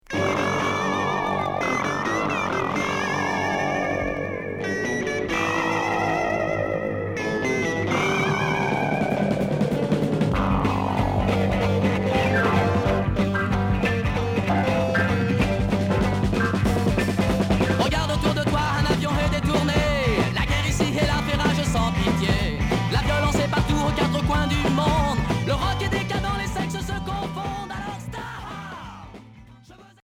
Glam rock Septième 45t retour à l'accueil